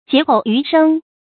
劫后余生 jié hòu yú shēng 成语解释 劫：灾难。经历灾难之后幸存下来的人。
成语繁体 刼后余生 成语简拼 jhys 成语注音 ㄐㄧㄝ ˊ ㄏㄡˋ ㄧㄩˊ ㄕㄥ 常用程度 常用成语 感情色彩 中性成语 成语用法 偏正式；作谓语、定语、宾语；含褒义 成语结构 偏正式成语 产生年代 近代成语 成语正音 劫，不能读作“jiē”。